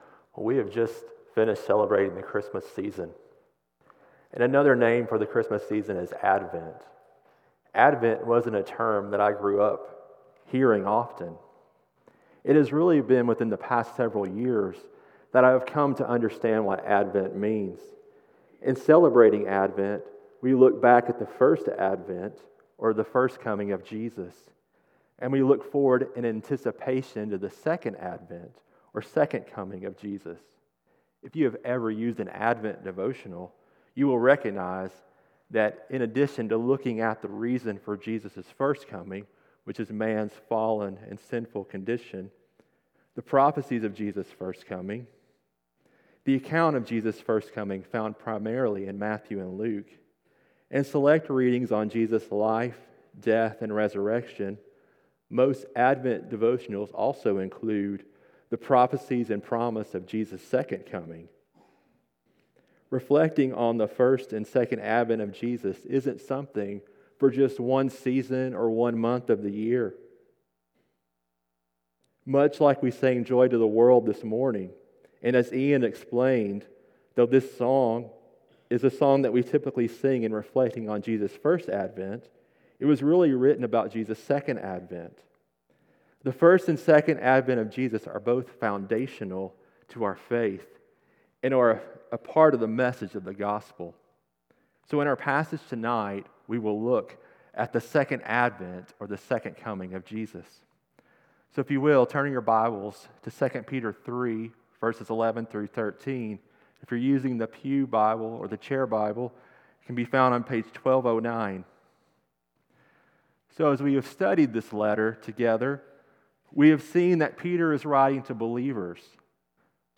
CCBC Sermons 2 Peter 3:11-13 Jan 05 2025 | 00:23:10 1x 00:00 / 00:23:10 Subscribe Share Apple Podcasts Spotify Overcast RSS Feed Share Link Embed